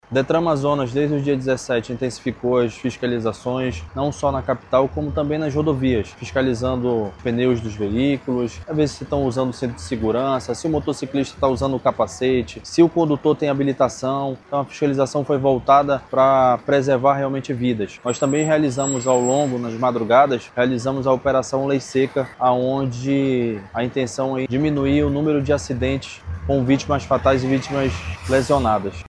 As infrações mais contabilizadas foram: conduzir veículo não licenciado (68), dirigir sob a influência de álcool (64), conduzir motocicleta sem capacete de segurança (44), conforme explica o diretor-presidente do Detran Amazonas, David Fernandes.